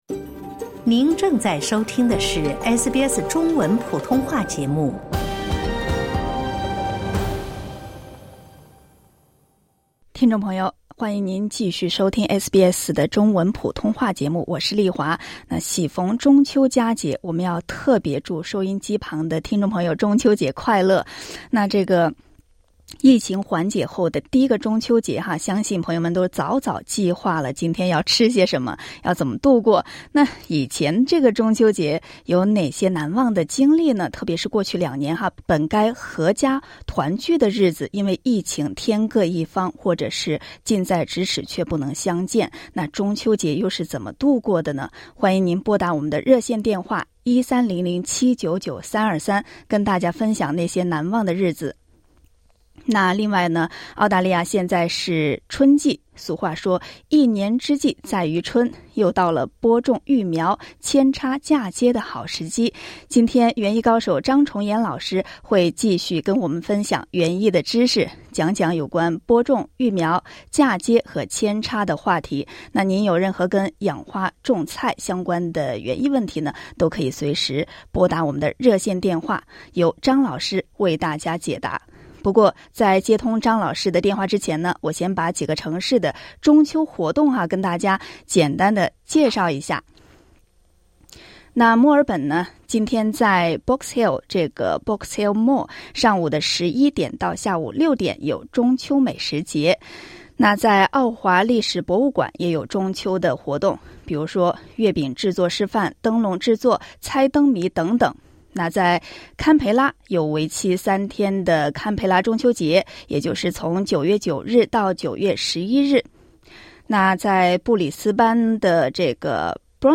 听众朋友将跟您分享。